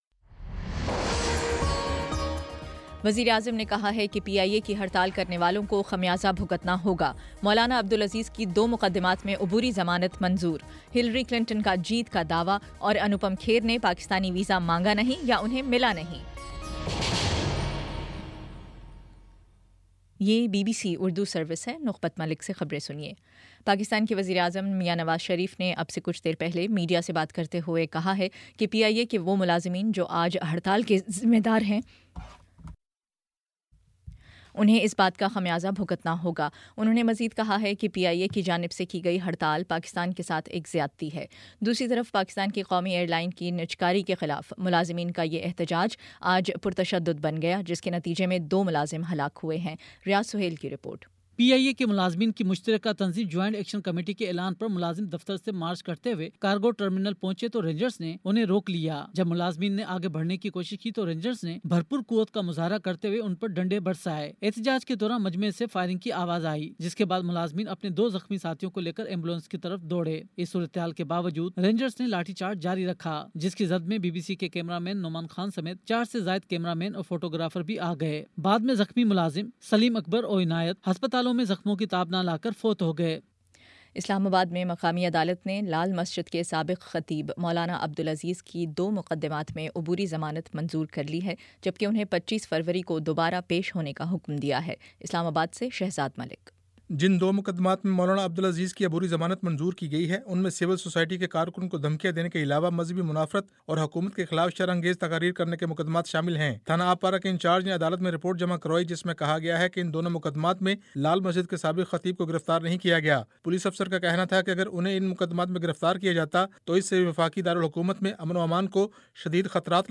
فروری 02 : شام چھ بجے کا نیوز بُلیٹن